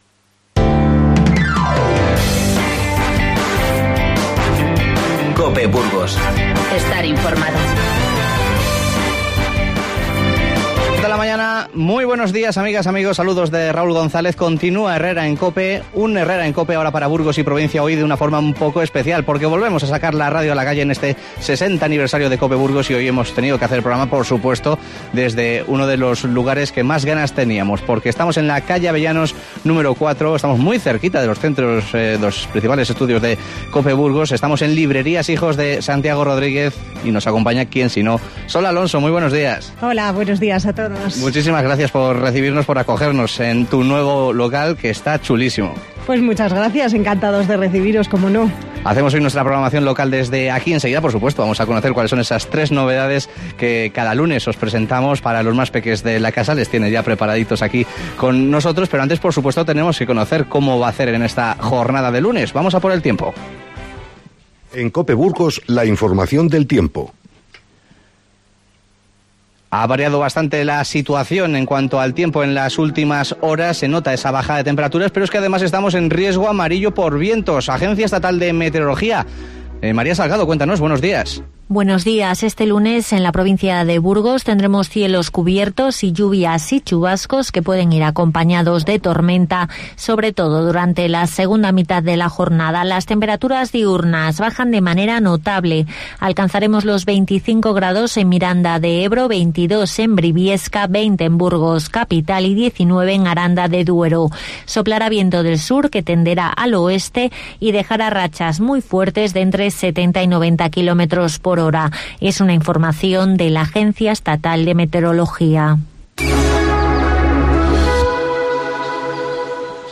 Hoy hacemos nuestra programación local desde Librerías Hijos de Santiago Rodrígez.